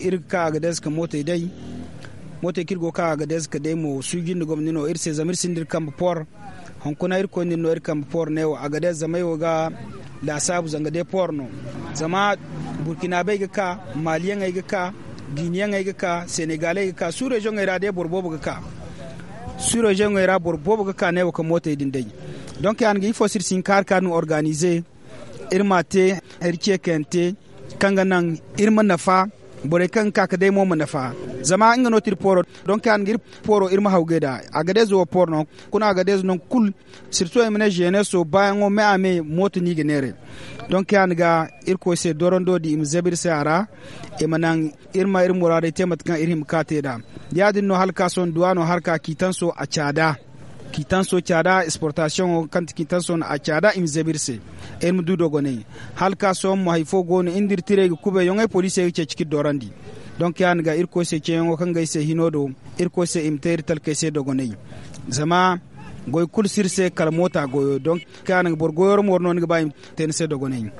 Ecoutez le témoignage de ce jeune homme il fait la navette entre Niamey et Agadez pour faire fructifier sa petite affaire…